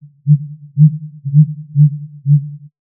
Grouse like to spend their time “hooting” while hidden in a tree:
Grouse Hooting
grouse.wav